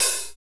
Index of /90_sSampleCDs/Northstar - Drumscapes Roland/DRM_Hip-Hop_Rap/HAT_H_H Hats x
HAT H H LH05.wav